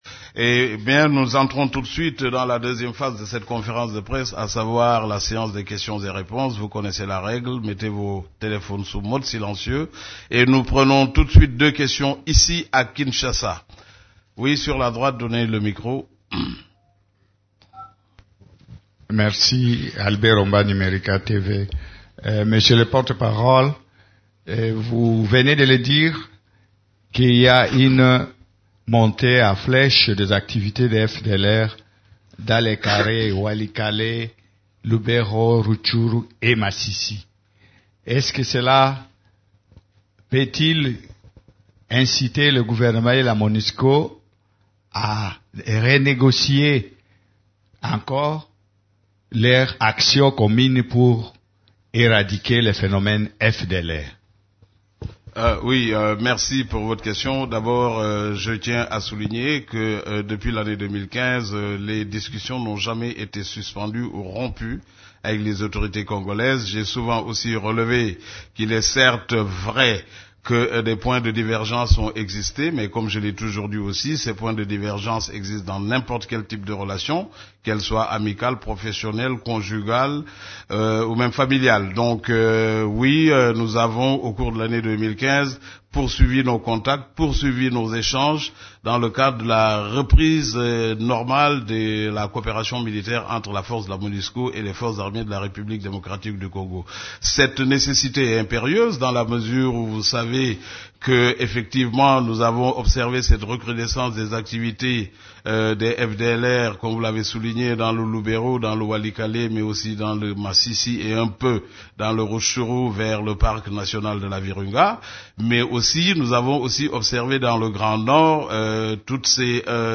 Conférence de presse du 27 janvier 2016
Les activités des composantes de la Monusco et la situation militaire ont été au centre de la conférence de presse hebdomadaire des Nations unies du mercredi 27 janvier à Kinshasa.
Voici la première partie de la conférence de presse: